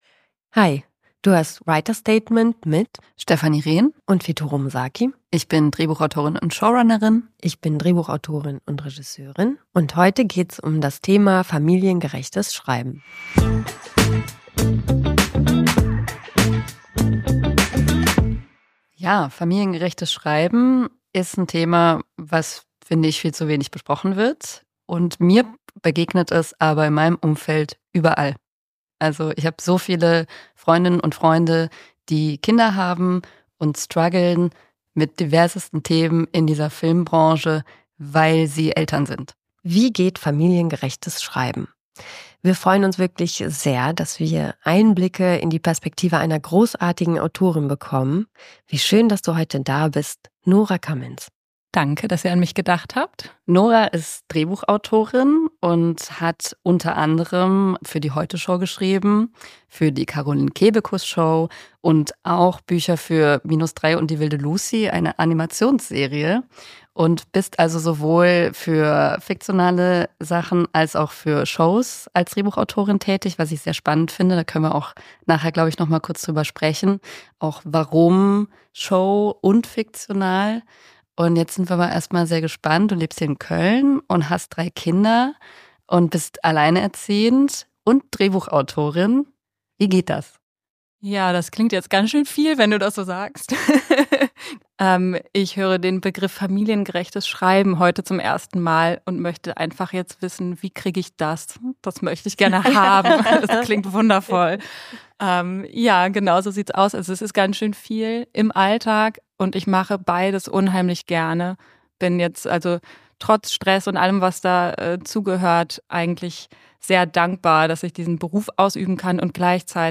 Ein Gespräch mit Drehbuchautorin